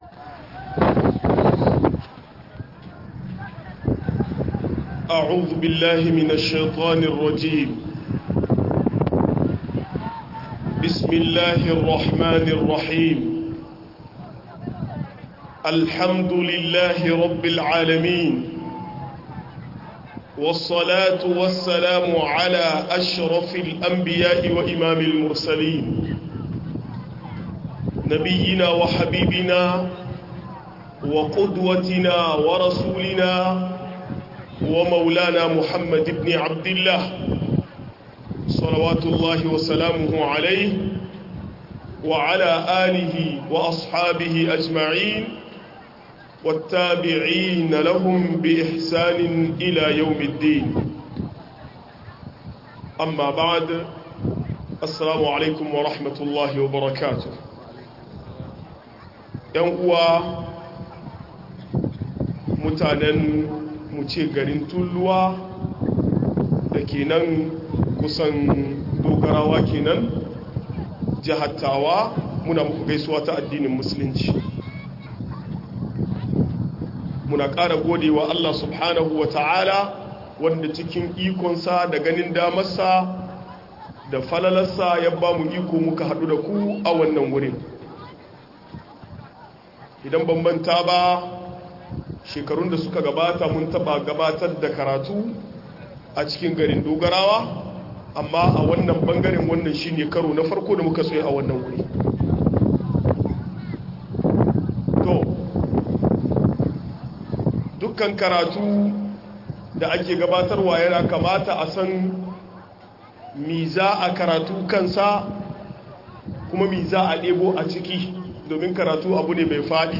Jahilci da illolin sa - MUHADARA